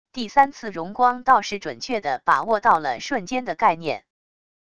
第三次荣光倒是准确的把握到了瞬间的概念wav音频生成系统WAV Audio Player